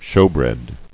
[shew·bread]